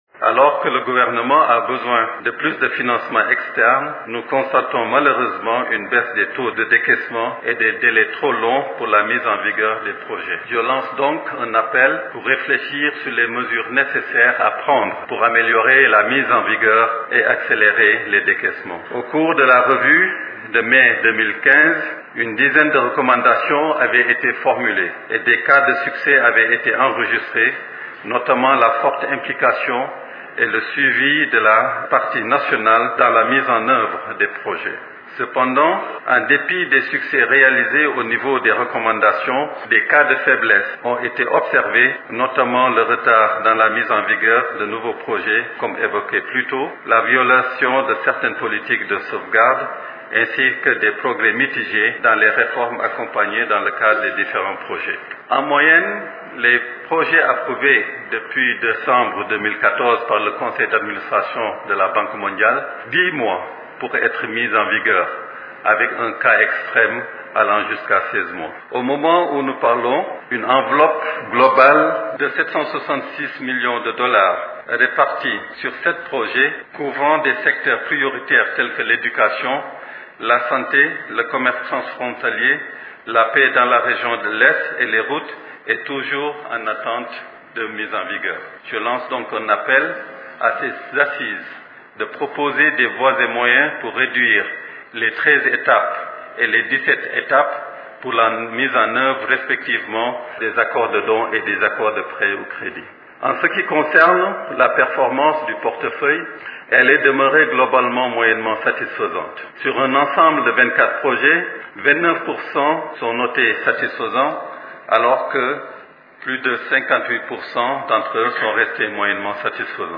Dans cet extrait sonore, il appelle les participants à ces travaux à réfléchir sur les mesures nécessaires à prendre pour améliorer les décaissements: